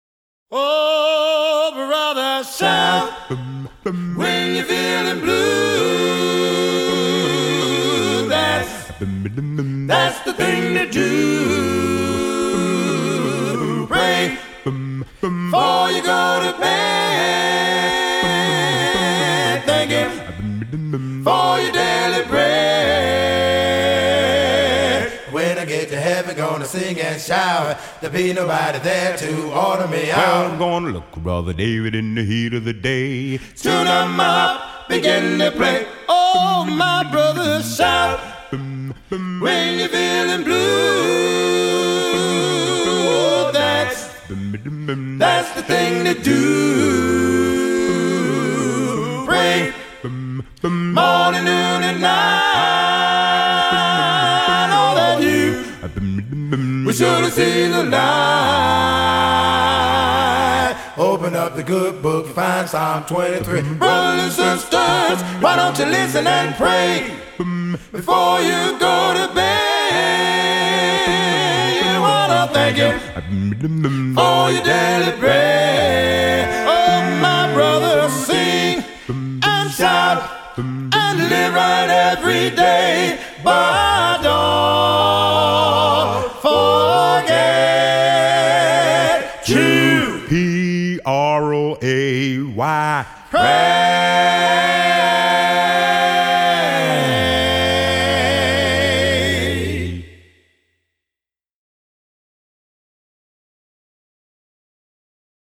Excerpt of traditional spiritual "Don’t Forget to Pray" performed by The Paschall Brothers from the album Songs for Our Father, used courtesy of the Virginia Foundation for the Humanities.